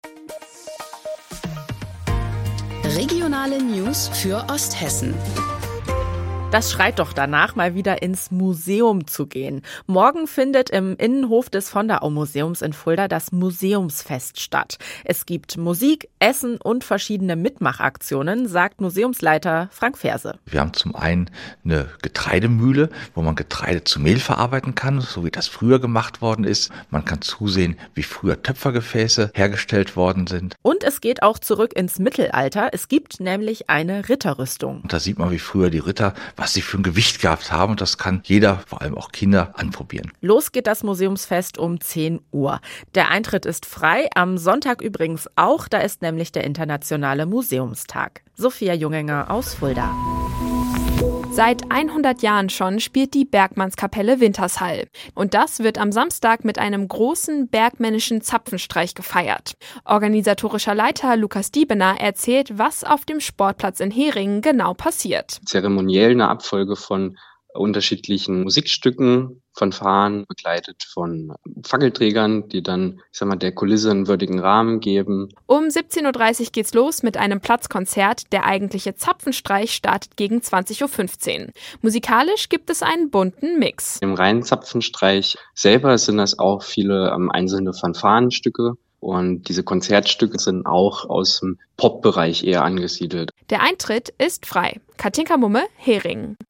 Mittags eine aktuelle Reportage des Studios Fulda für die Region